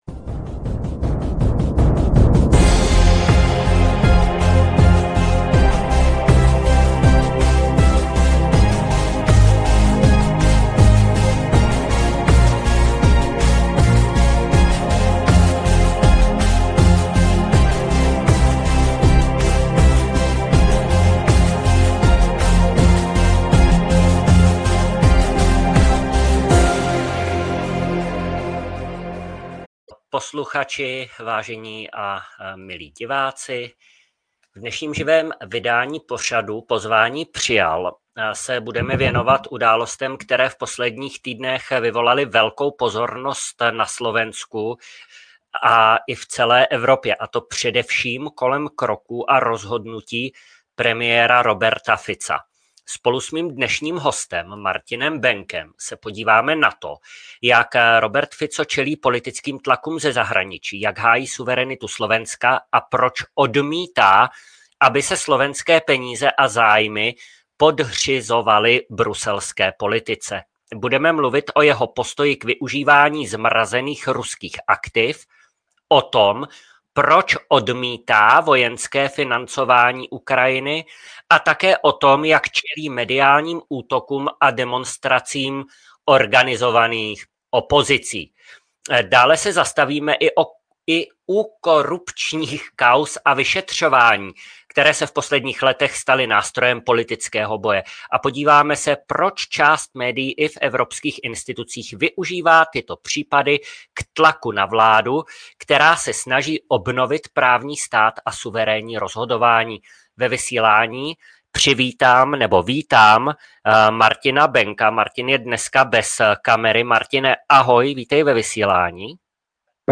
V dnešním živém vysílání Studia Berlín rozebíráme aktuální dění na Slovensku a v Evropské unii. Premiér Robert Fico čelí tvrdé kritice liberálních médií i opozice, zatímco prosazuje suverénní a pragmatickou politiku, která odmítá další vyzbrojování Ukrajiny a varuje před konfiskací ruského majetku, jež by podle něj jen prodloužila válku.